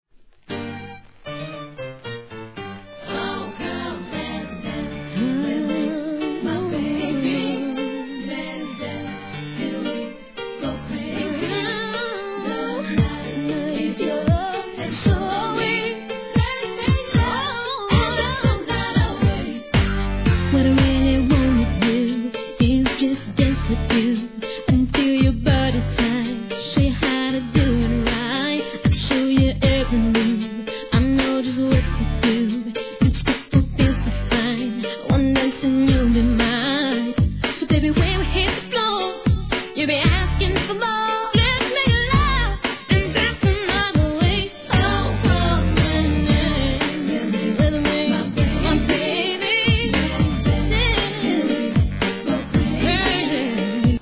HIP HOP/R&B
タンゴ・リメイクでの2000年作品!!